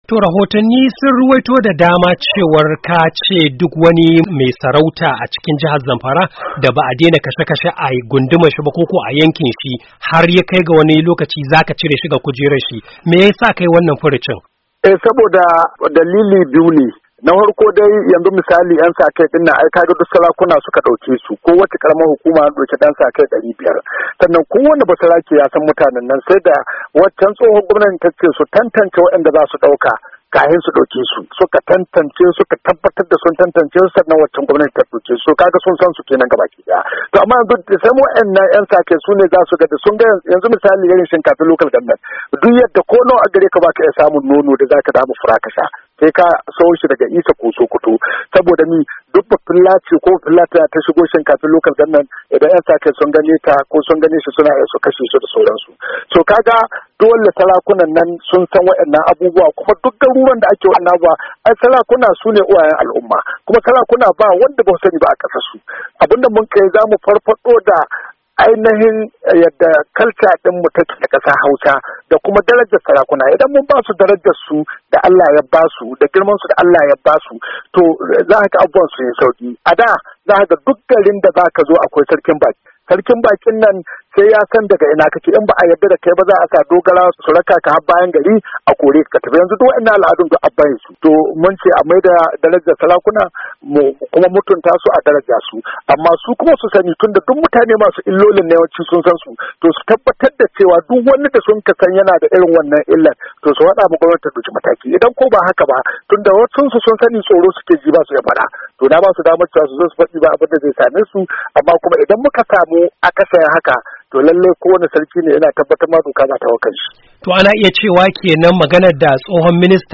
Gwamnan ya bayyana hakan ne yayin wata zantawa da ya yi da Muryar Amurka inda ya ce suna da rahotanni da suka tabbatar masu cewar gwamnatin da ta gabata ta daukin 'yan saka kai a kowacce karamar hukuma, karkashin jagorancin sarakunan yankunan.